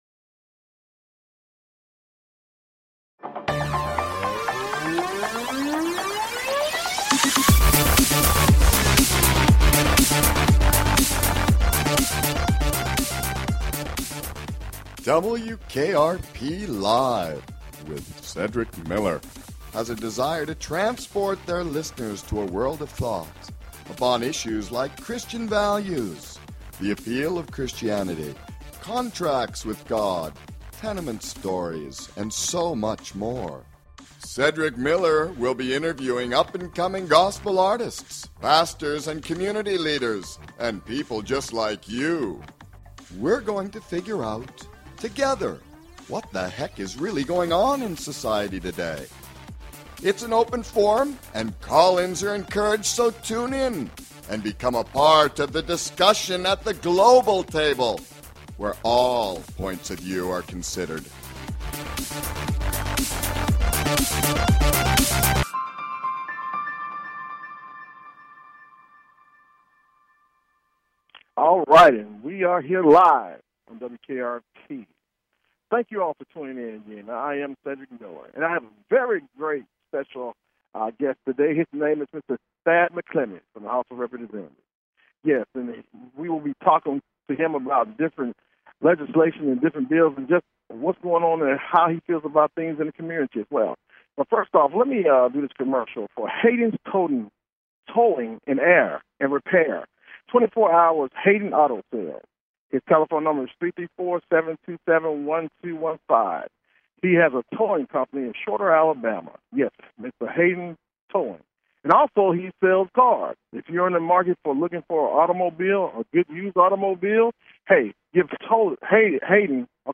Talk Show Episode, Audio Podcast, WKRP Live and Guest, Thad McClammy on , show guests , about Thad McClammy, categorized as News,Philosophy,Politics & Government,Religion,Self Help
Guest, Thad McClammy